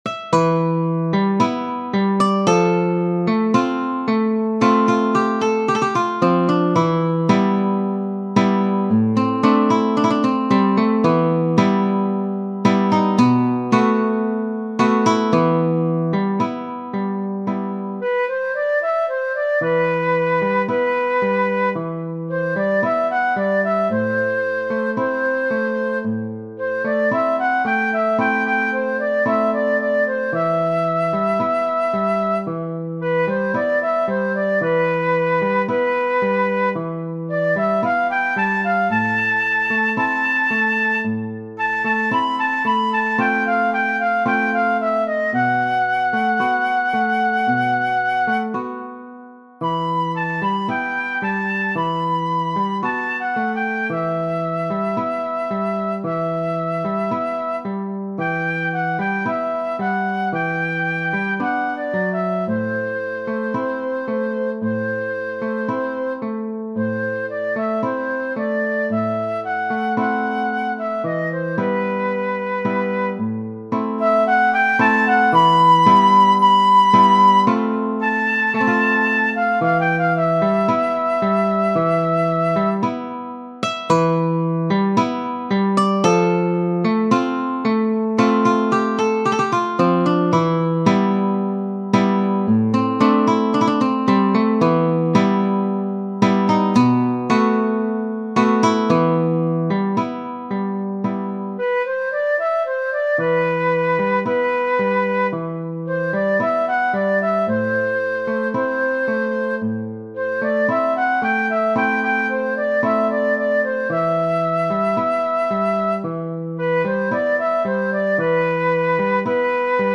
Genere: Napoletane